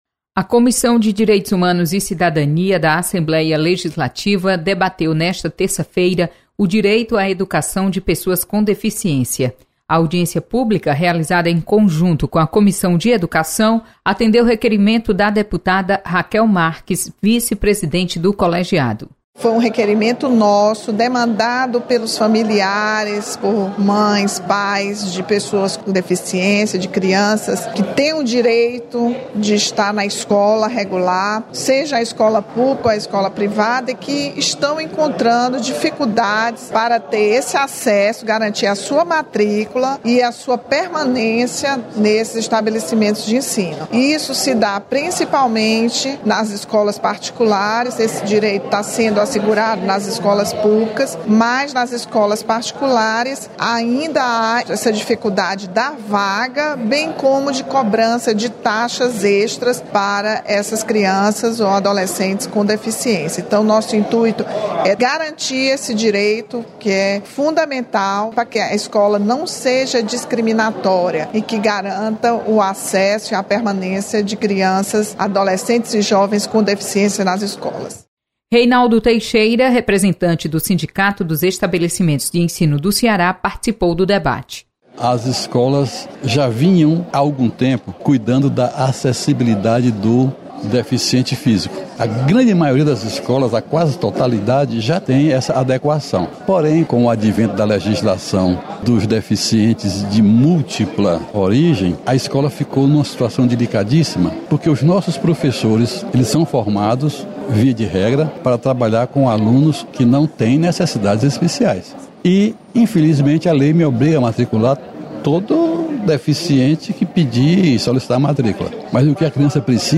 Você está aqui: Início Comunicação Rádio FM Assembleia Notícias Audiência